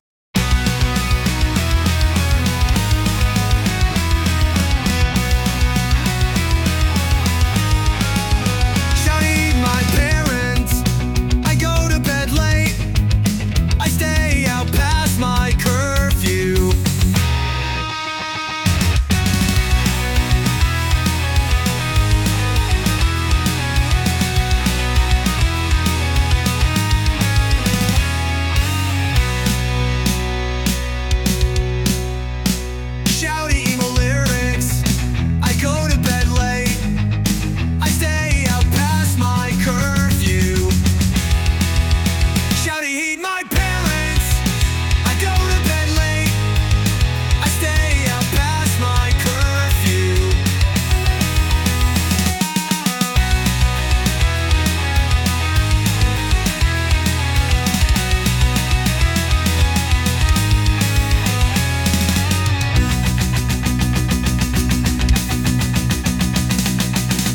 shouty emo lyrics